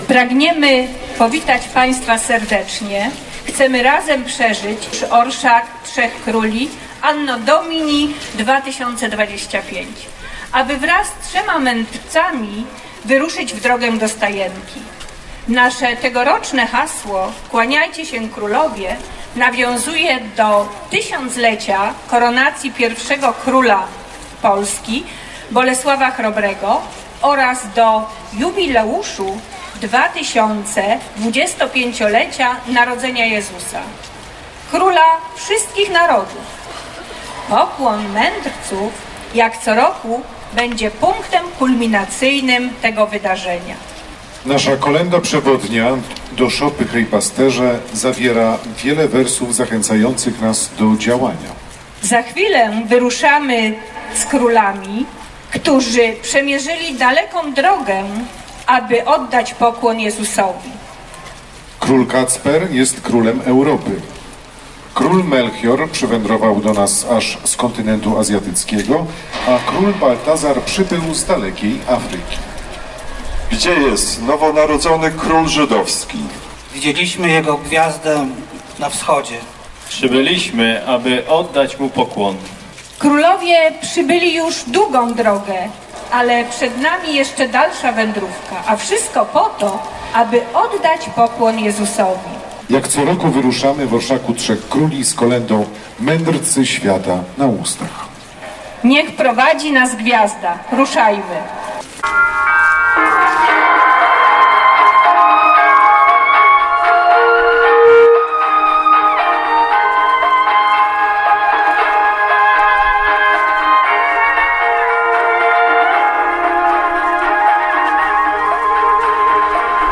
Po liturgii, uczestnicy orszaku prowadzeni przez trzech monarchów oraz Gwiazdę Betlejemską, przy dźwiękach kolęd, wyruszyli do parku przy pałacu.